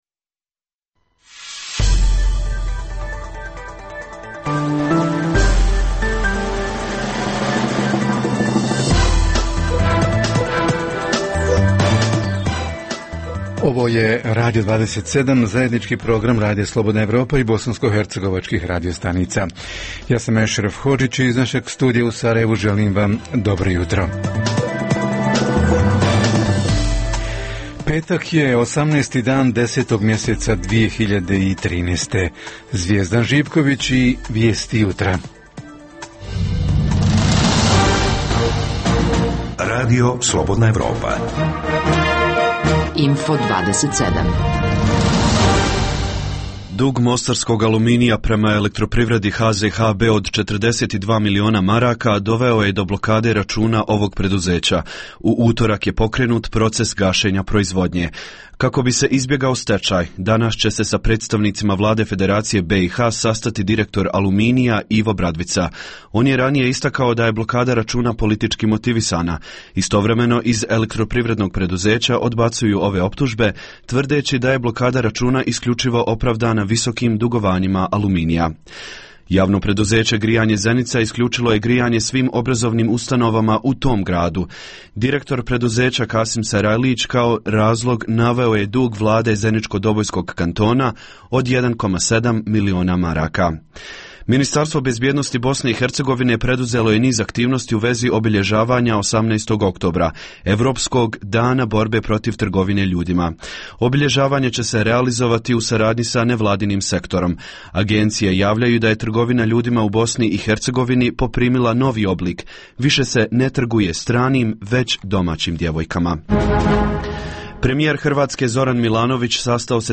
O tome „javljanja uživo“, izvještaji i tonske bilješke naših reportera, a u završnici ovog jutra – redovna rubrika „Za zdrav život“ i – čitanjem protiv stresa.